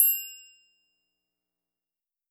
Willare Perc (21).wav